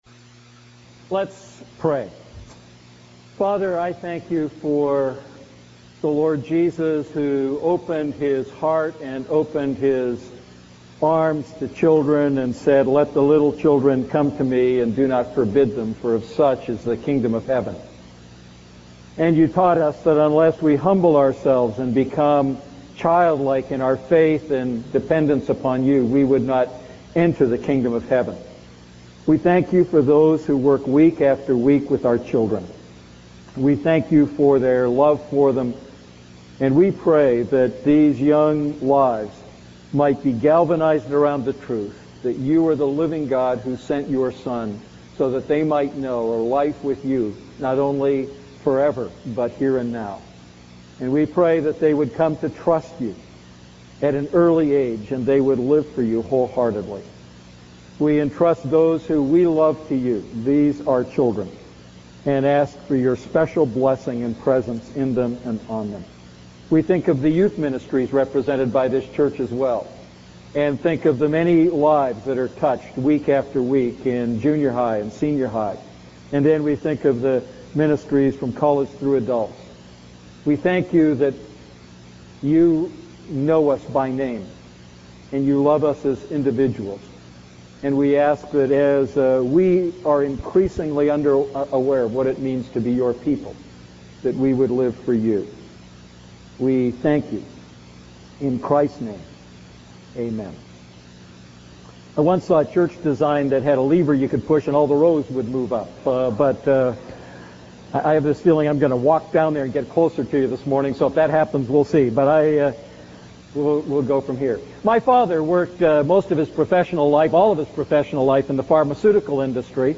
A message from the series "Ephesians Series II."